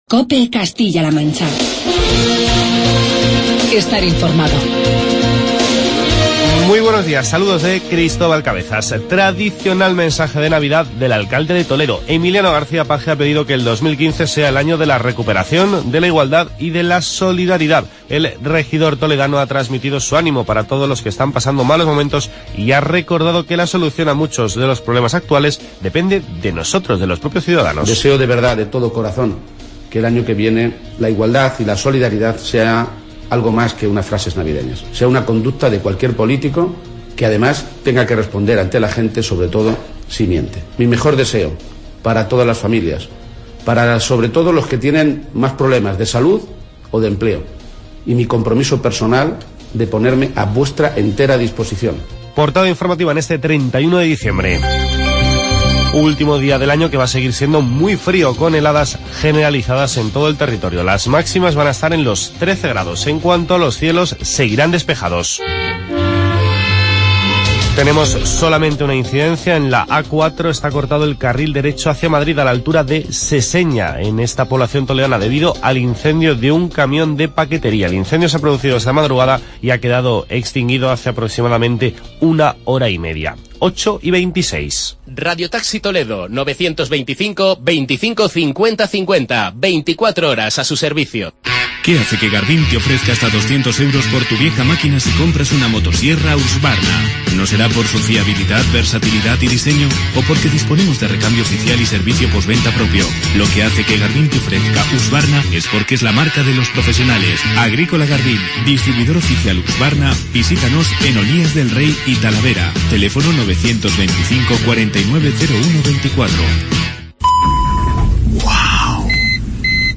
Escuchamos el mensaje de Navidad del alcalde de Toledo, Emiliano García-Page